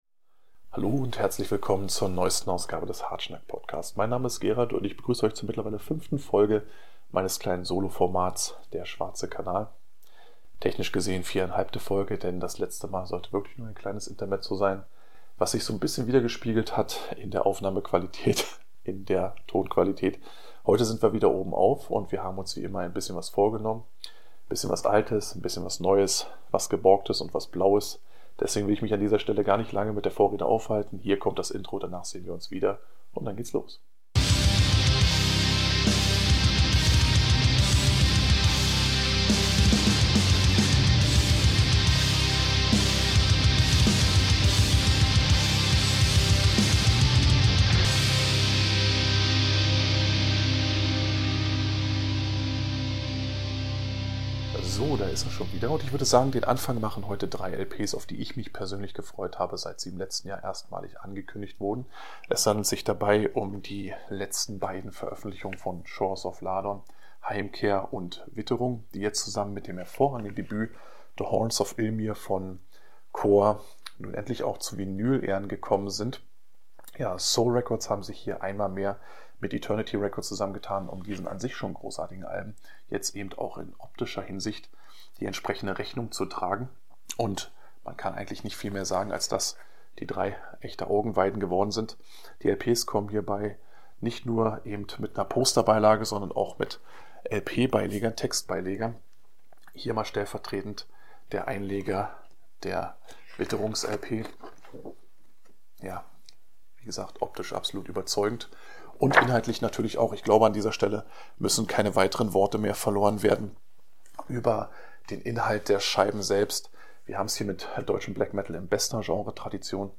Hartschnack - der Extremmetal Podcast